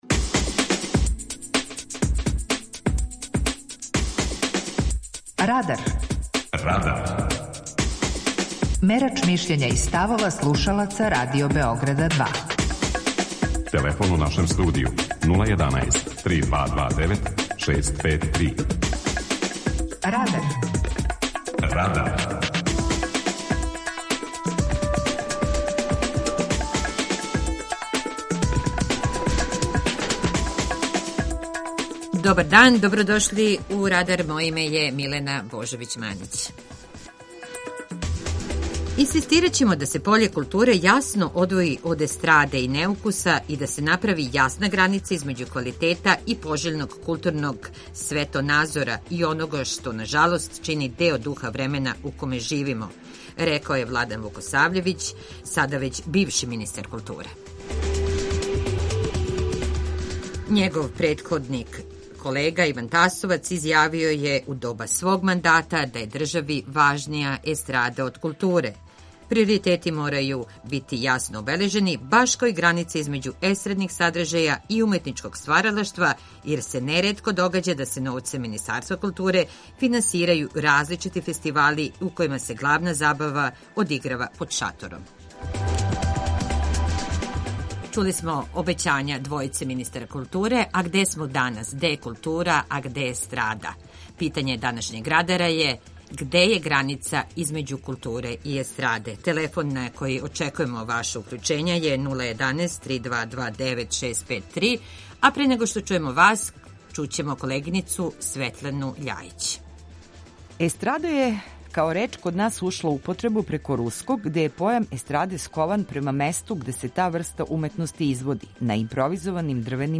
Питање Радара: Где је граница између културе и естраде? преузми : 18.92 MB Радар Autor: Група аутора У емисији „Радар", гости и слушаоци разговарају о актуелним темама из друштвеног и културног живота.